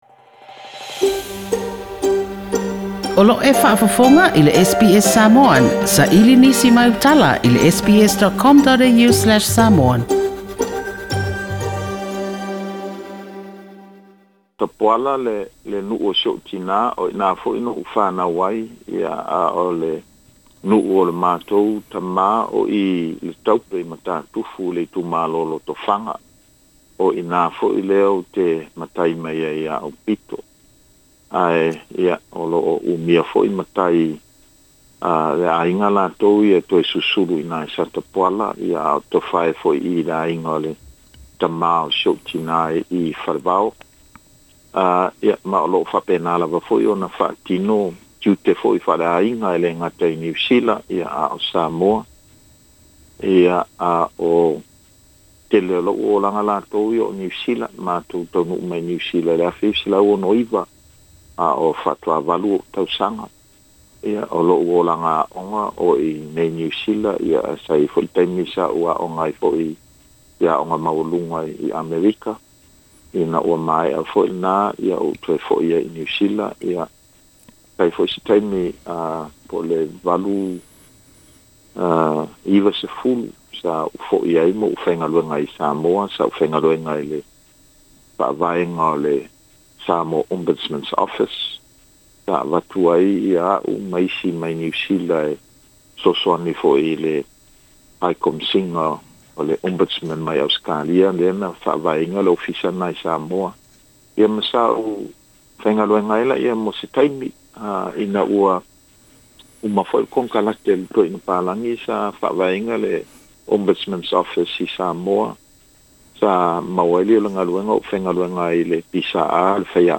I le tatou polokalame o le 'Talanoa' i lenei masina, o se talanoaga ma le Afioga Aupito William Sio, le minisita o mataupu i tagata Pasefika i le Kapeneta o le faigamalo i Aotearoa, o ia fo'i o le minisita lagolago o le matagaluega o le tulafono ma faamasinoga i Niu Sila.